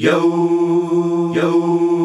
YOOOOH  F.wav